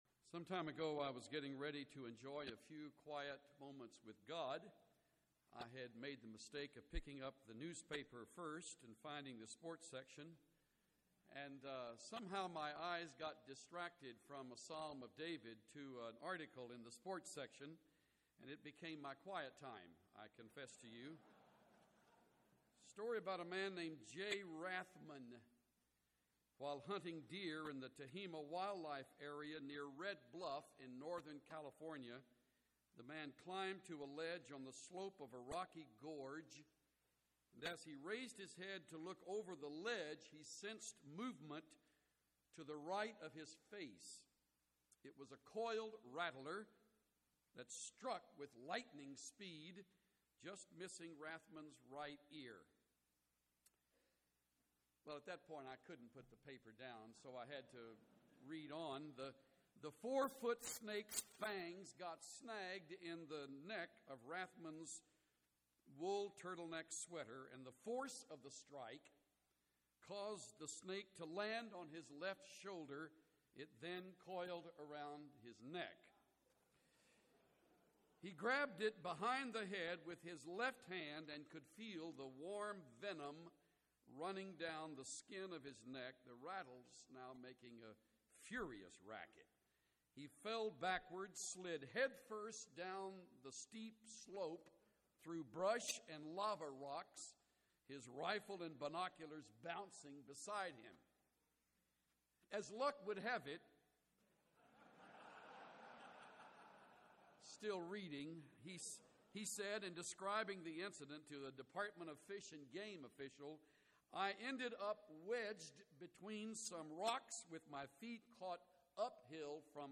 Chuck Swindoll teaches on David and Goliath and David's victory through faith in the Lord.